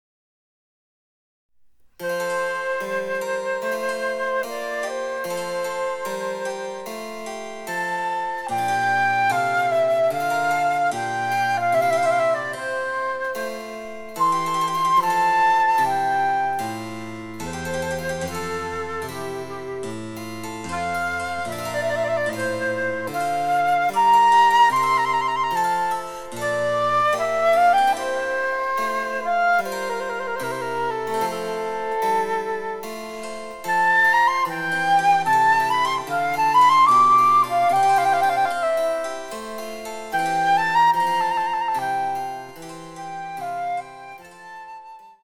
■フルートによる演奏
チェンバロ（電子楽器）